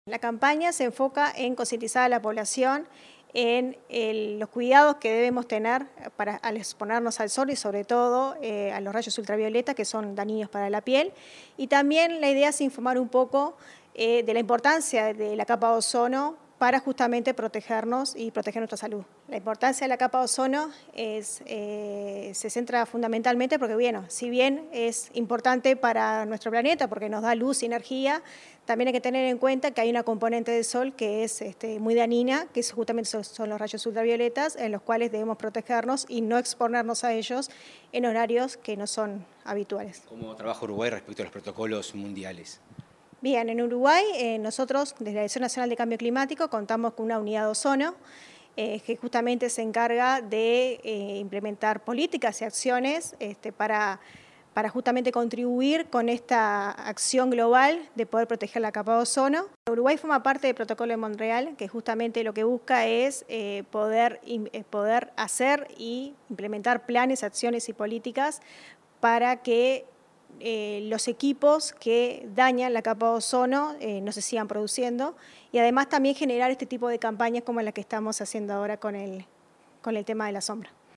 Entrevista a Natalie Pareja, directora nacional de Cambio Climático